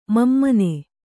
♪ mammane